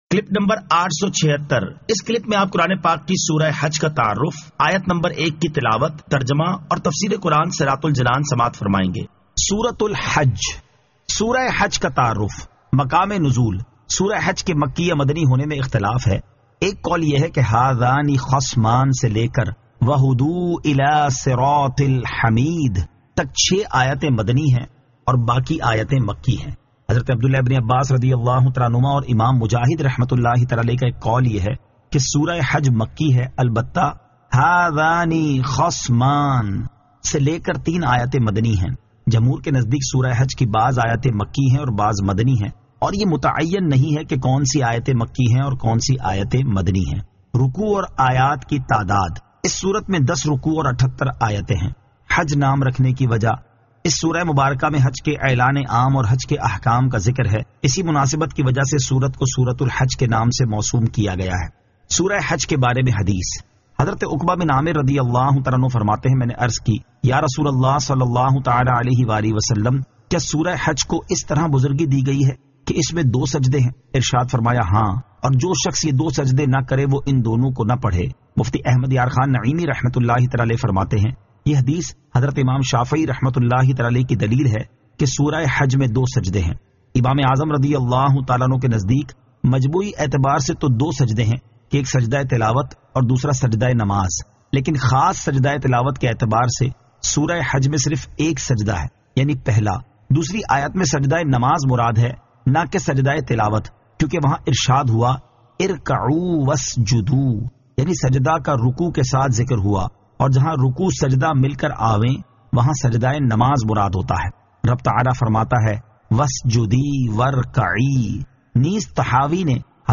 Surah Al-Hajj 01 To 01 Tilawat , Tarjama , Tafseer